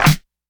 Dilla L Clap 04.wav